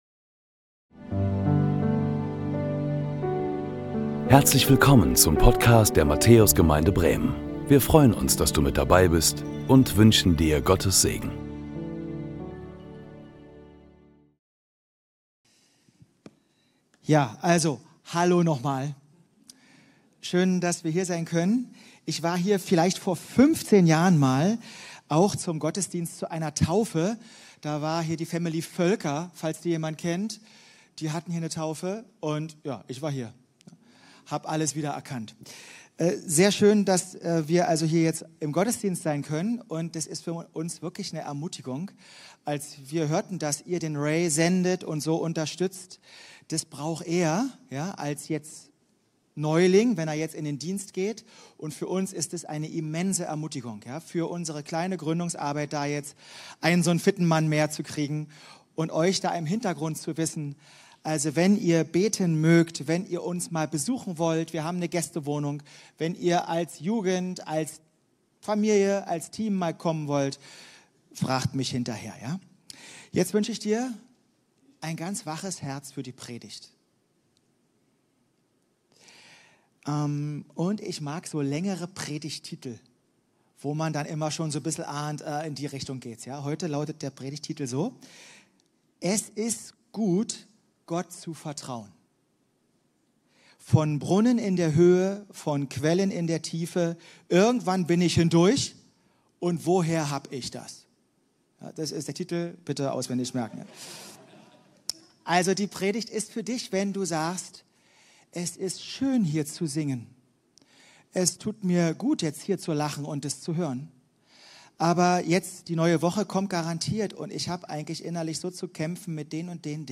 Predigten der Matthäus Gemeinde Bremen Es ist gut, Gott zu vertrauen.